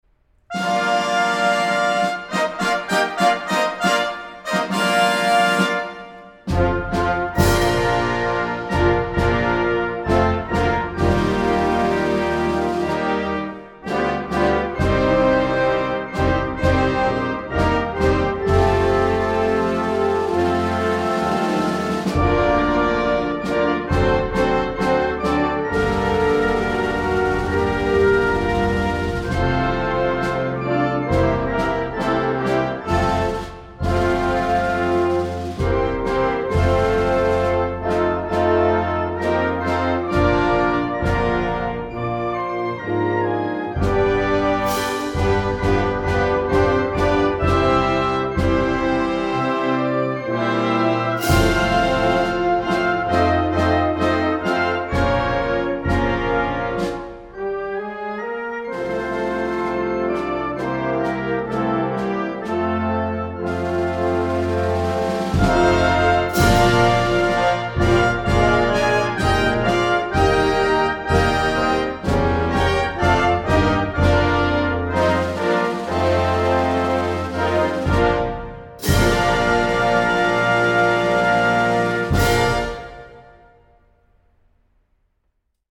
Georgian_anthem.mp3